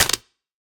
Minecraft Version Minecraft Version latest Latest Release | Latest Snapshot latest / assets / minecraft / sounds / block / mangrove_roots / step4.ogg Compare With Compare With Latest Release | Latest Snapshot
step4.ogg